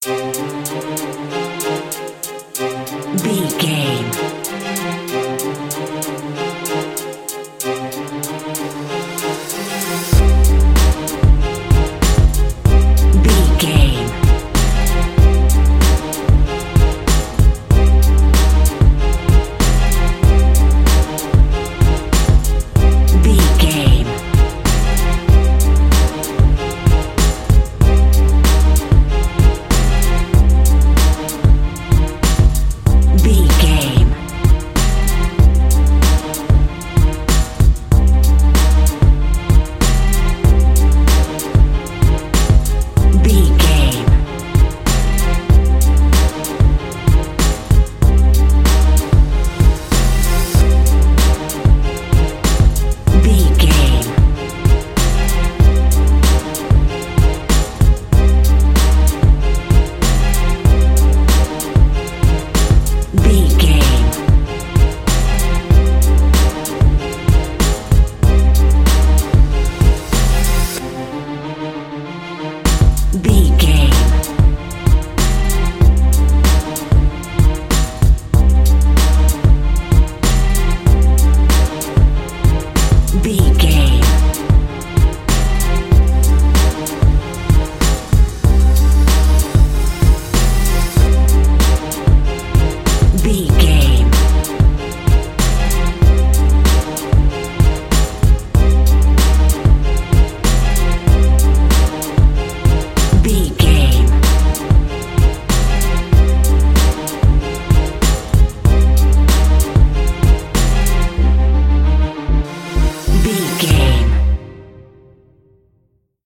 Aeolian/Minor
synthesiser
strings
hip hop
soul
Funk
neo soul
acid jazz
confident
energetic
bouncy
funky